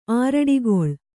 ♪ āraḍigoḷ